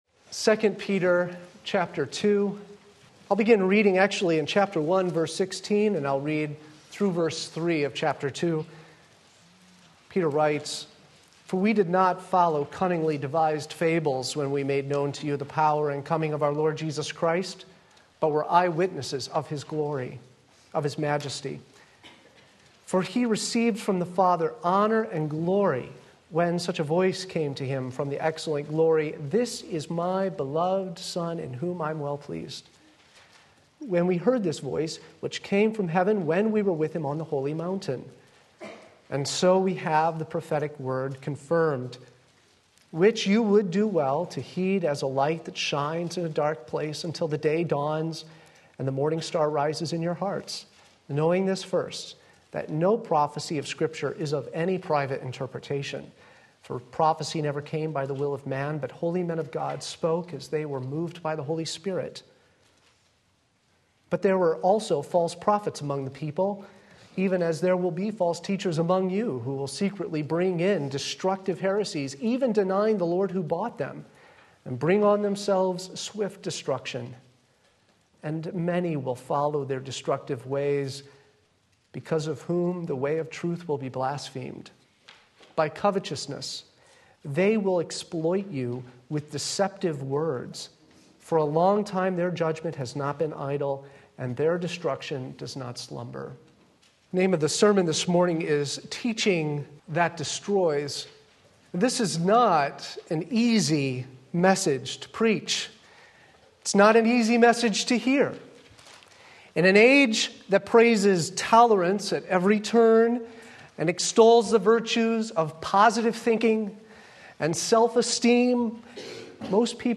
Sermon Link
Teaching That Destroys 2 Peter 2:1-3 Sunday Morning Service